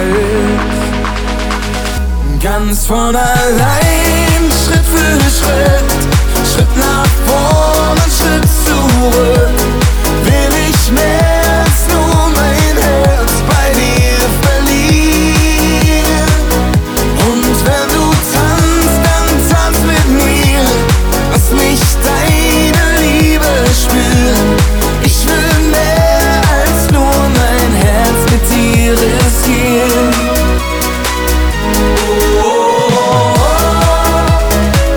German Pop
Жанр: Поп музыка